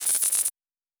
Sci-Fi Sounds / Electric / Glitch 3_05.wav
Glitch 3_05.wav